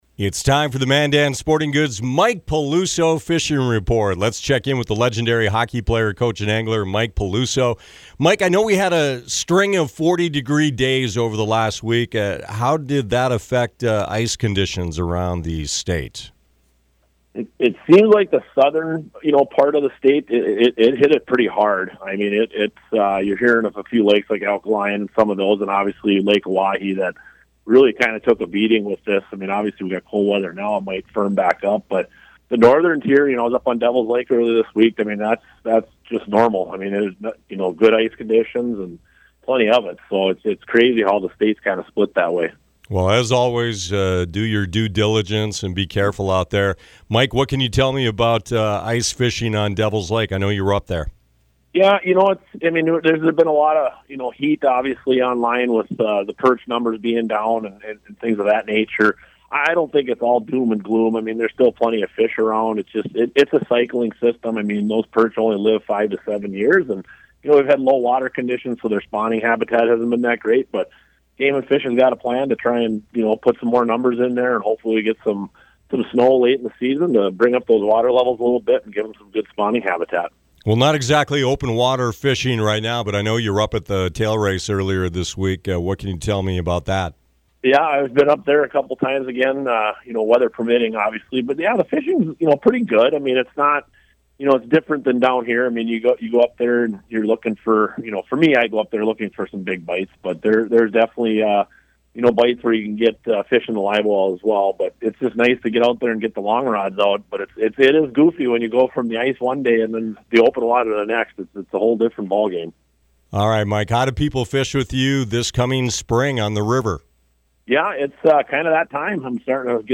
North Dakota Fishing Reports Latest Radio Report 2-6-26 Missouri River Fishing Report-April 19th, 2026 Apr 19, 2026 For some reason this spring the winter weather just doesn't want to give up her bounty.